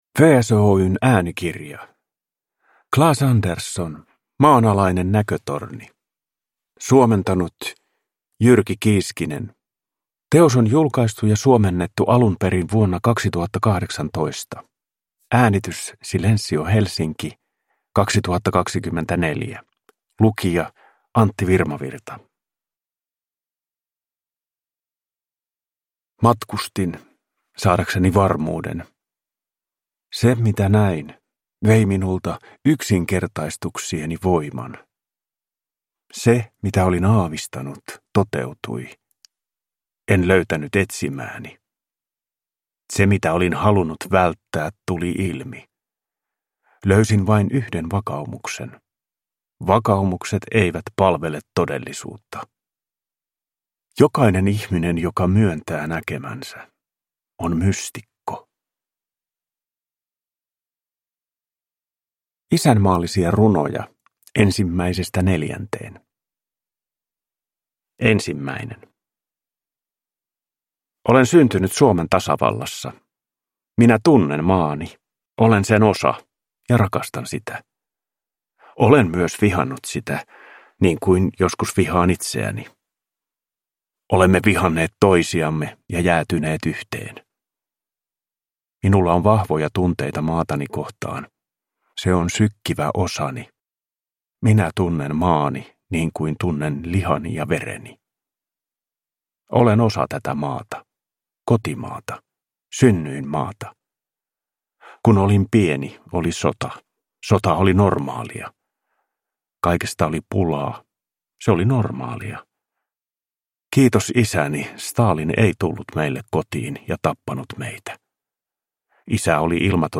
Lyrik Njut av en bra bok